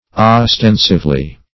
ostensively - definition of ostensively - synonyms, pronunciation, spelling from Free Dictionary Search Result for " ostensively" : The Collaborative International Dictionary of English v.0.48: Ostensively \Os*ten"sive*ly\, adv.
ostensively.mp3